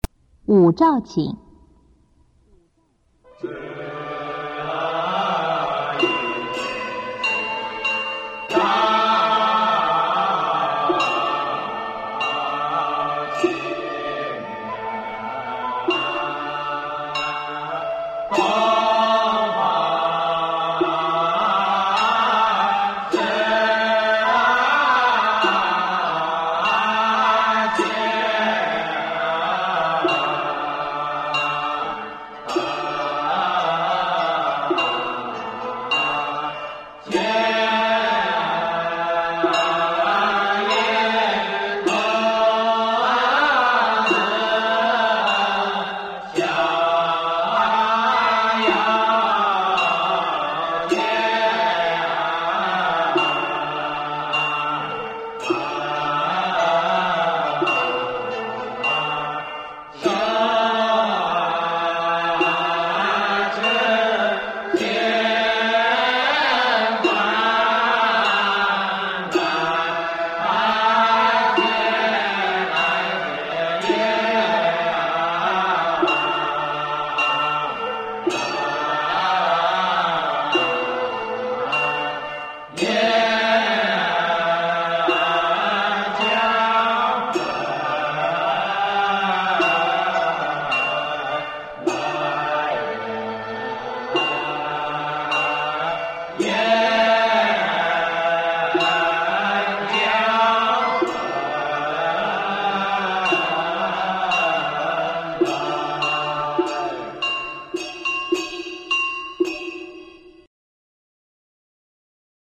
五召请赏析导读：五召请为祈求五方童子持幡召引五方幽魂，引至坛前，蒙受施食，旋律哀婉，句有召抚的力量。